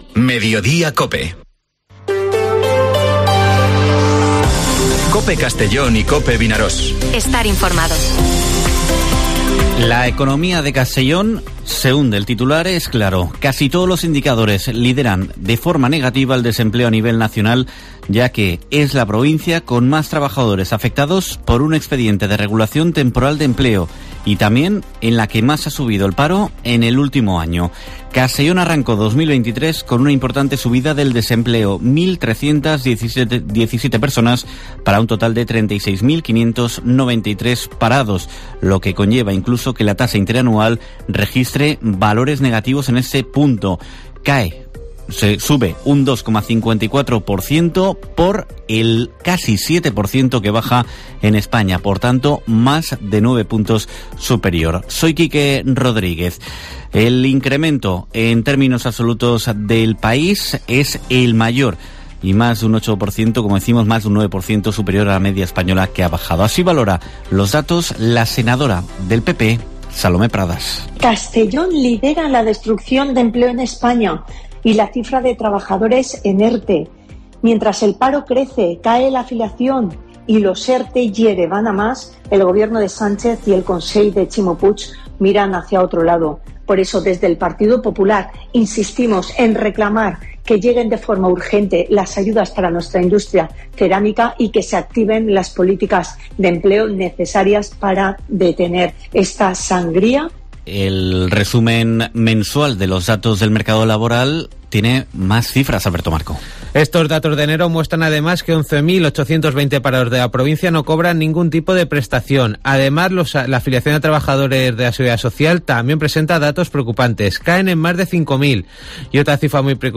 Informativo Mediodía COPE en la provincia de Castellón (02/02/2023)